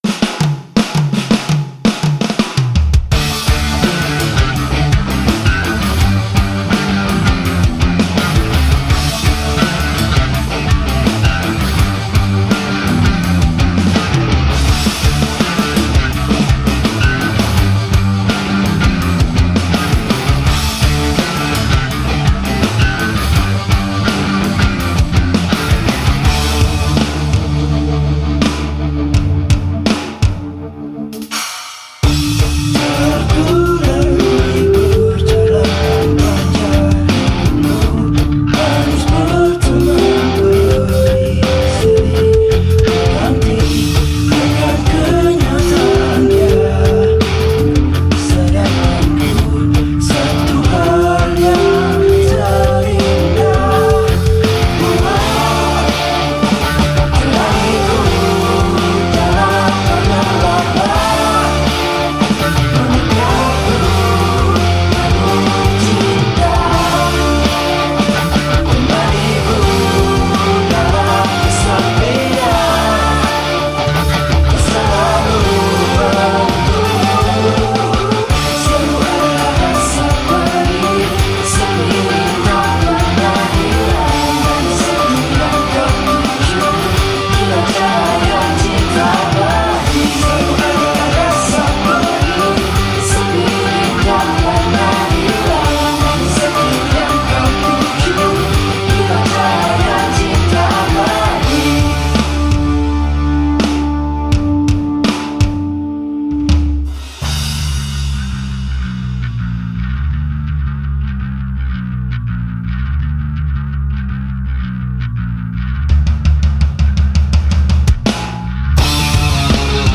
Malang Alternative
vokal
gitar
kibor
drum